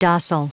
Prononciation du mot docile en anglais (fichier audio)
Prononciation du mot : docile